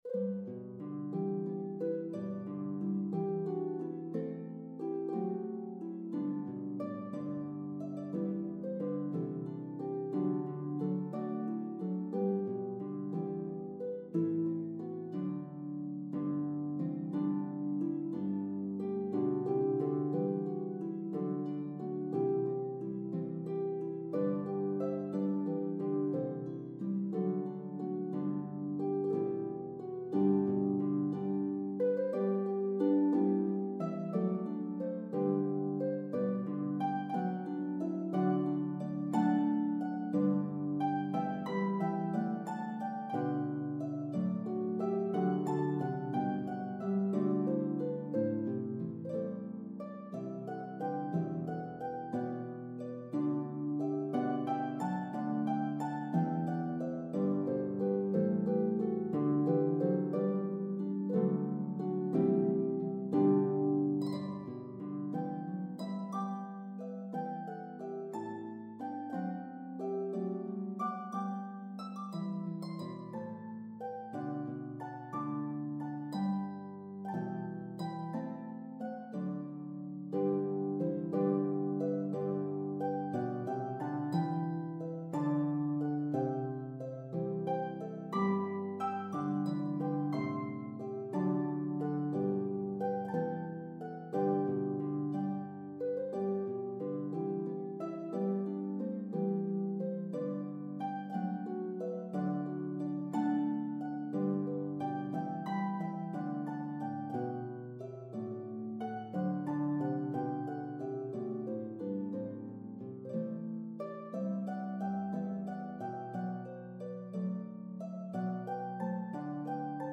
In the key of G, the melody is divided evenly between parts.